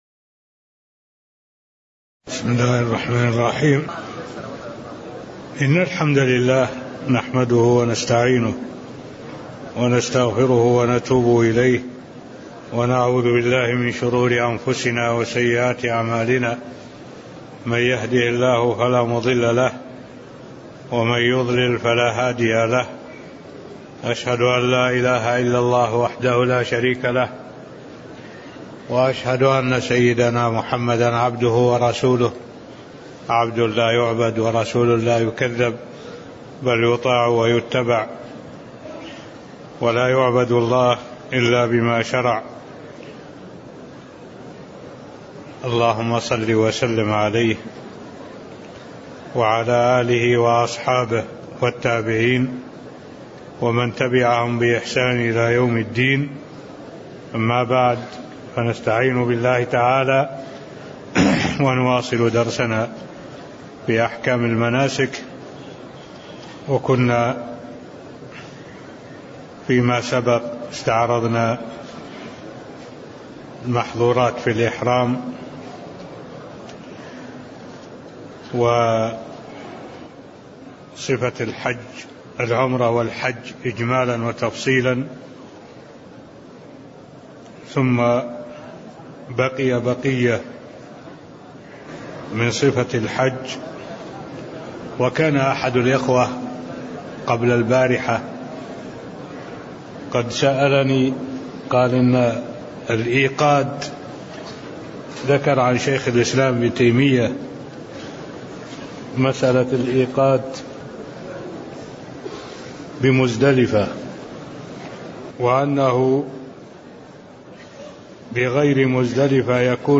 المكان: المسجد النبوي الشيخ: معالي الشيخ الدكتور صالح بن عبد الله العبود معالي الشيخ الدكتور صالح بن عبد الله العبود تكملة باب بيان محظورات الإحرام (03)